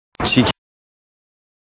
chick_prononciation.mp3